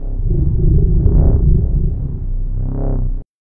~gravi_idle01.ogg